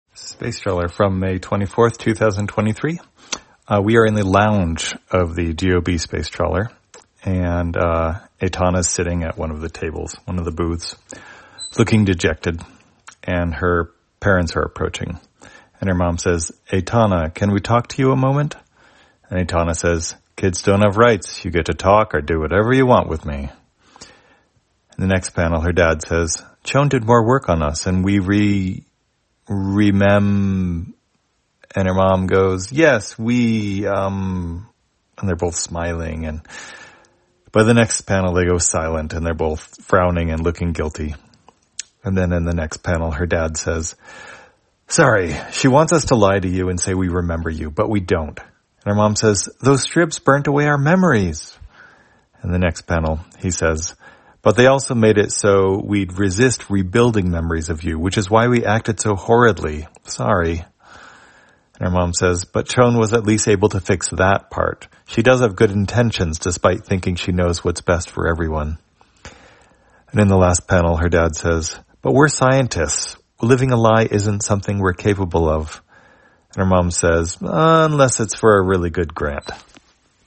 Spacetrawler, audio version For the blind or visually impaired, May 24, 2023.